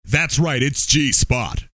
Tags: comedy announcer funny spoof crude radio